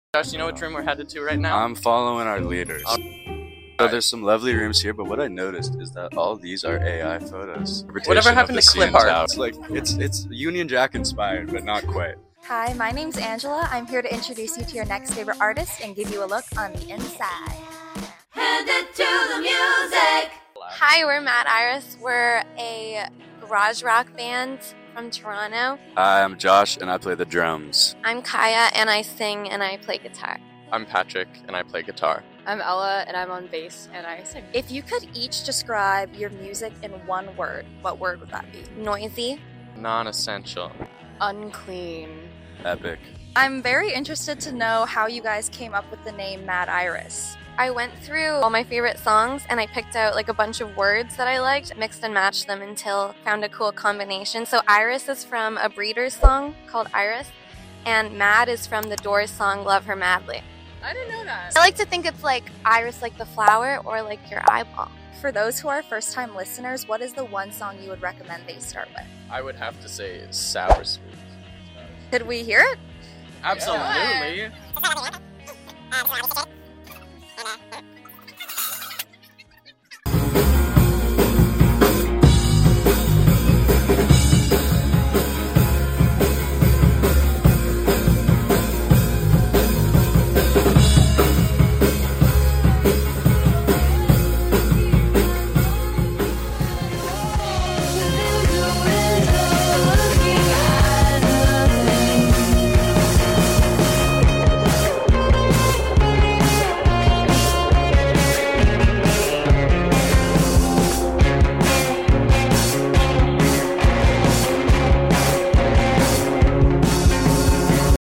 an edgy sound
grungy instrumentals
incredibly exciting vocals